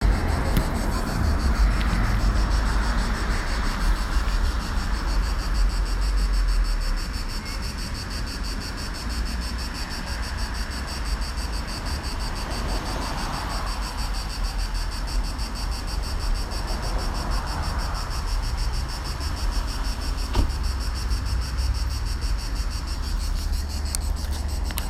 Hangos kabócaciripelést hallhattak a Posta-park szomszédságában sétálók | Váci Napló Online
kabócaciripelés-a-Posta-parknál.m4a